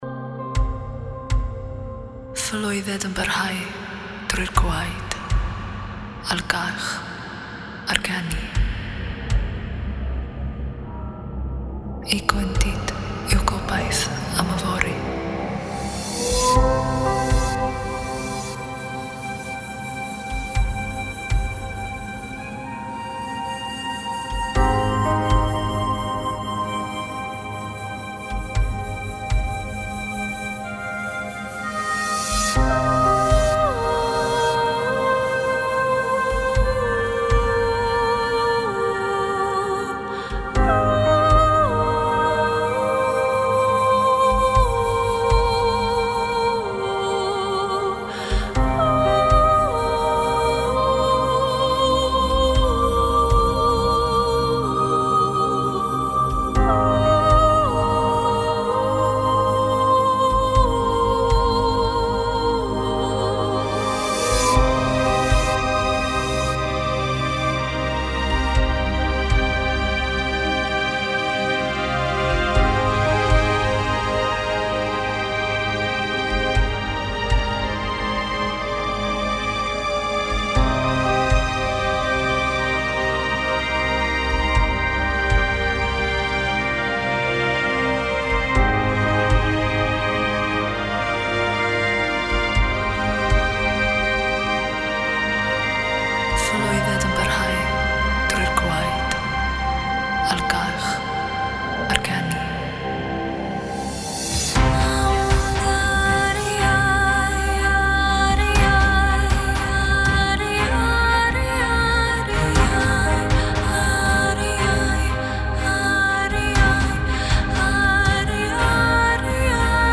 在此包装中，还包含带有英语翻译变体的纯正Elven短语，包括湿变体和干变体。
所有文件的节奏都相同，关键范围在C和Cm之间，从而使样本可以混合和匹配，以产生几乎无限量的变化和组合。